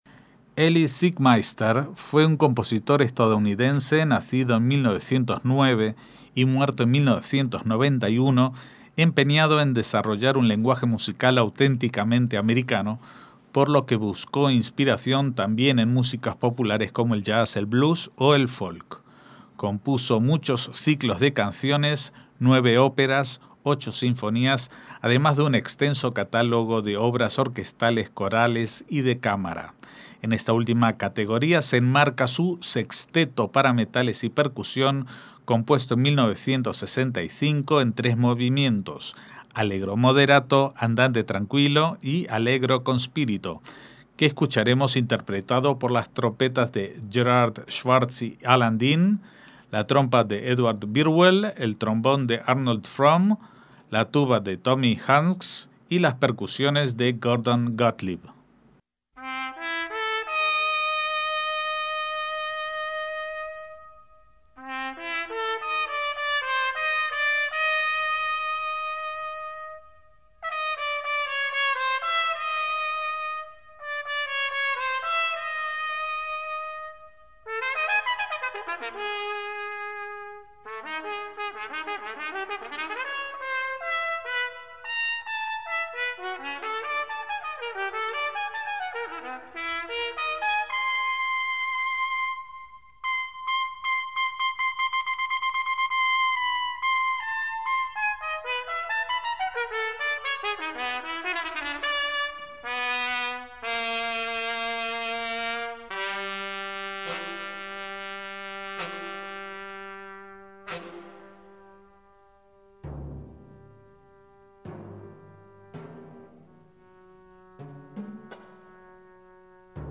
MÚSICA CLÁSICA
Escuchamos su Sexteto de metales